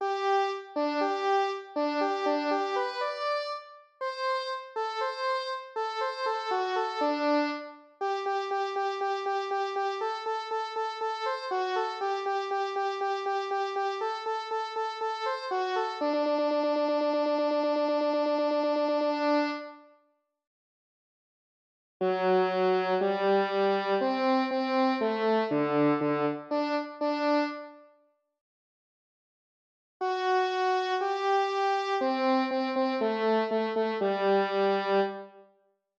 eine-kleine-cello.wav